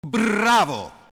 Bilux plaudenti